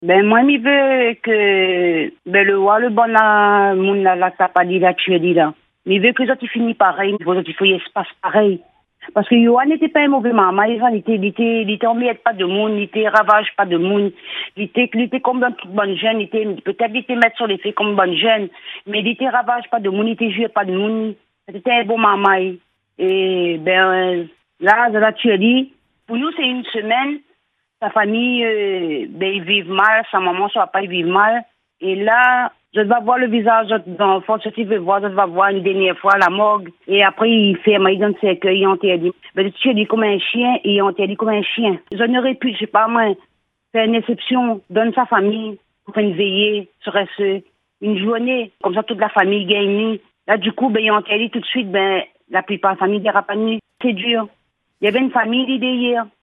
Elle s’est confiée au micro